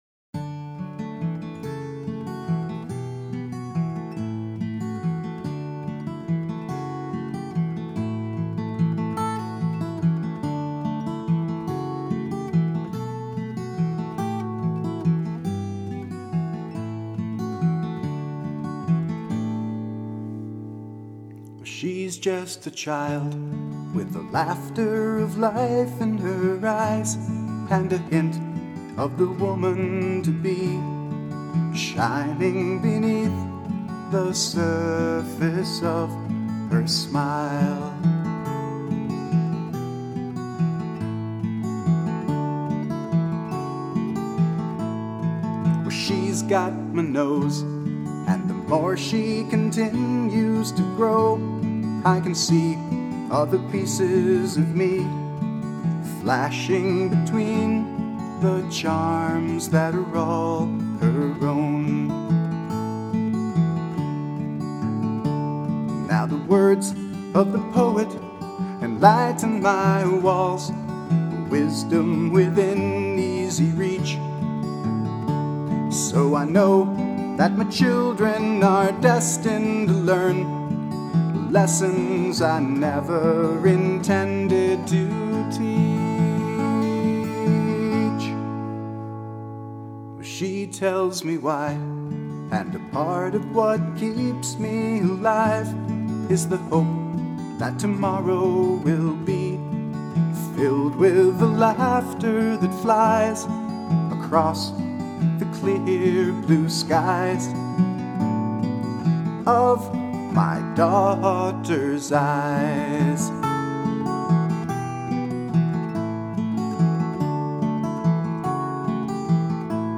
Guitar & Vocals